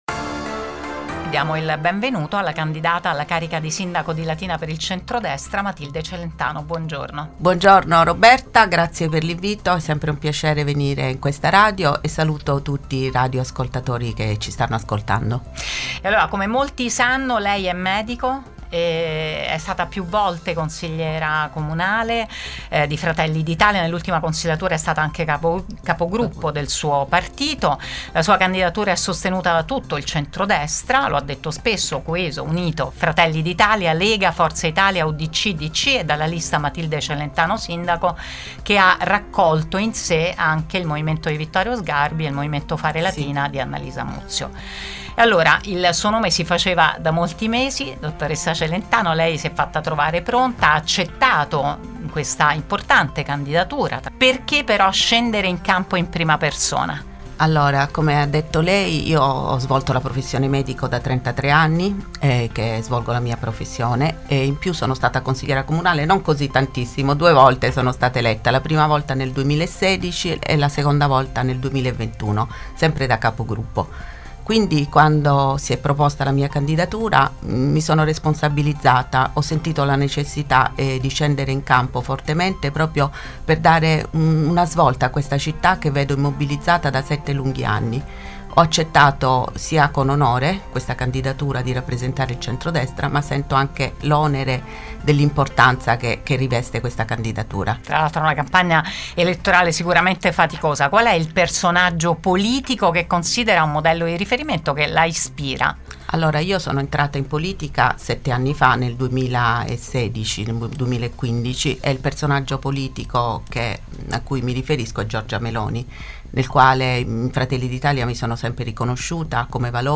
“Un onore essere stata candidata, ma avverto anche l’onere e la grande responsabilità verso la mia città”, ha detto intervenendo in diretta su Radio Immagine e su Immagine Tv (canale 212 del digitale terrestre) in apertura di un’agenda fittissima di impegni.